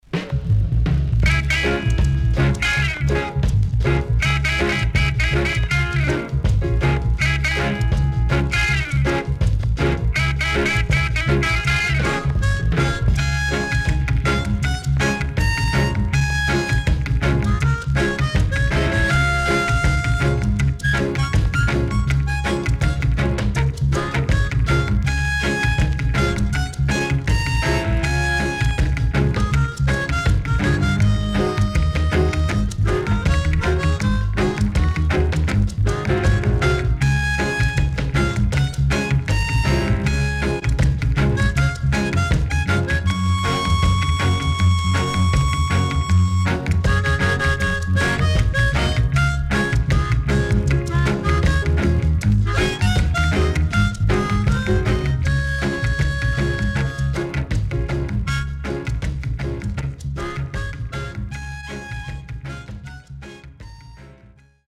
68年 W-Side Good Rocksteady Inst
SIDE A:プレスノイズ入ります。少しプチノイズ入ります。